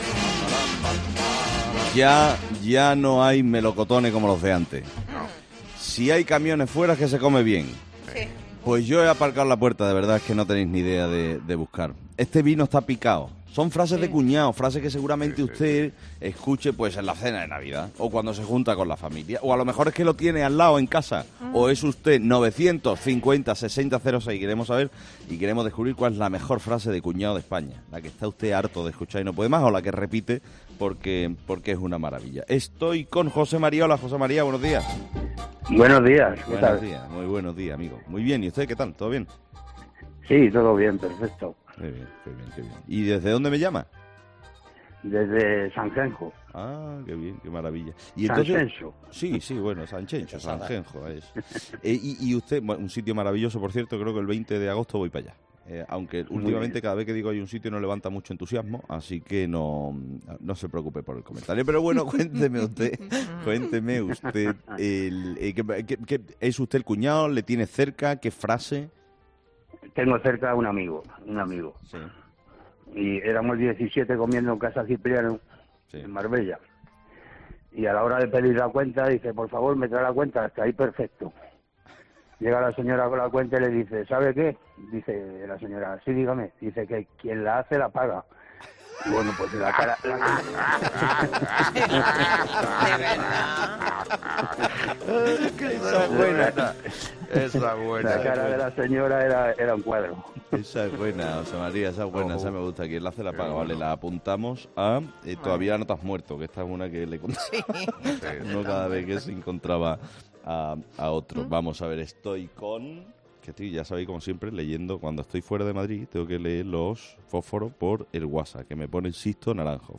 Hay un rato en 'Herrera en COPE' en el que charlamos con nuestros oyentes.
Un comentario que ha desatado las risas de los colaboradores del programa.